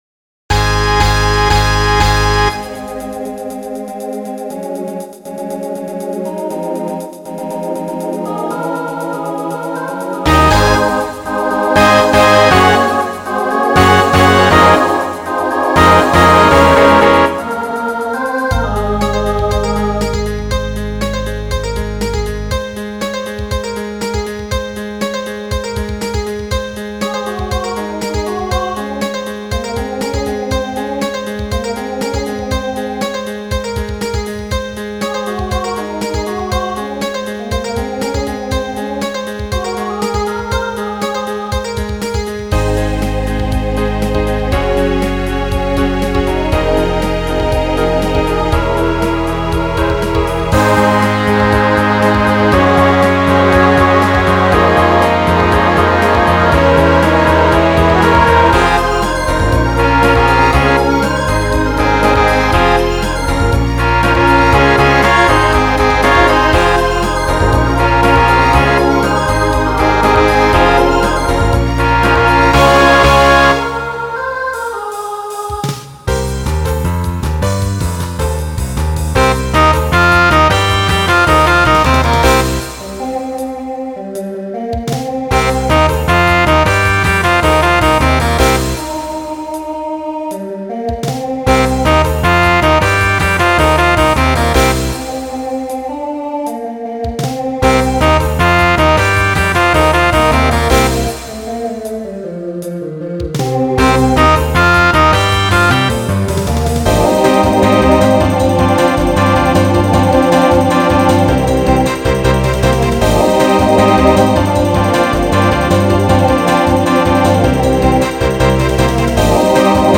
Starts SATB, then SSA, TTB, and finishes SATB.
Voicing Mixed
Genre Pop/Dance , Rock